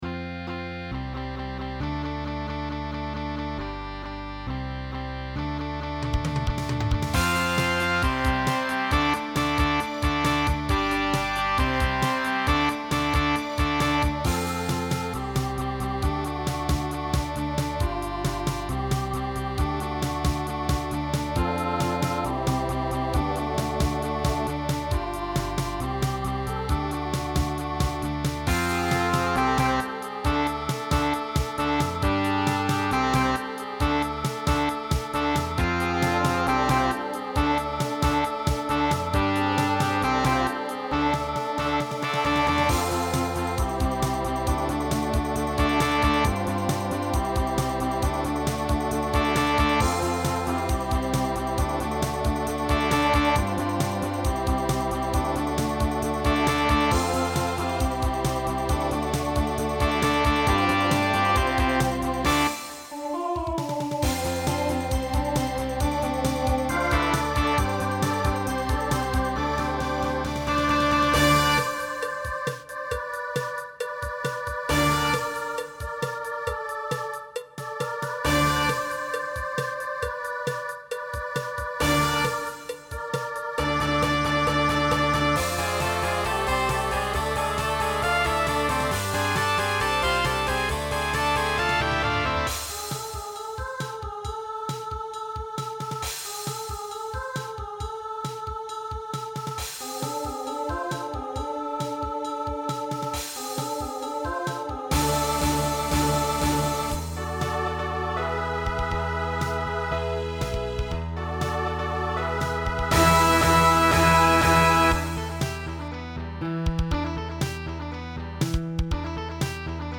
New TTB voicing for 2026.
TTB Instrumental combo Genre Pop/Dance , Rock Decade 1970s